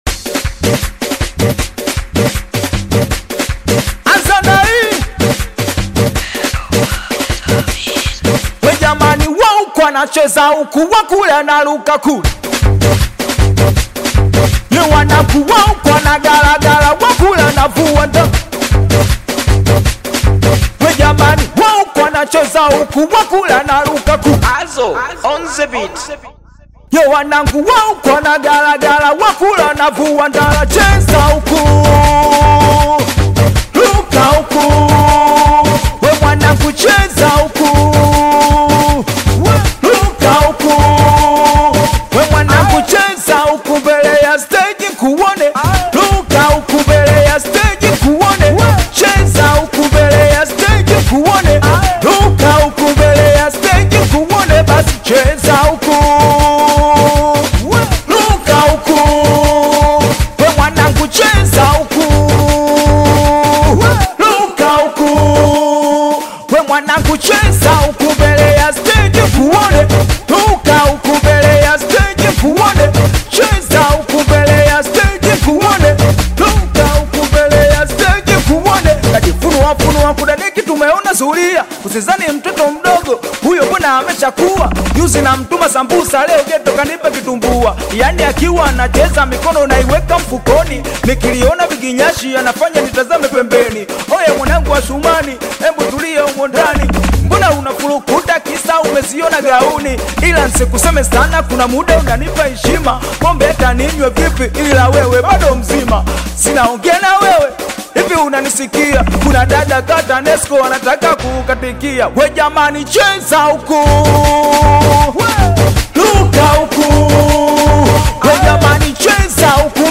AUDIO Singeli